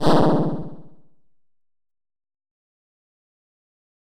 有关法术变化魔术音效的演示模板_风云办公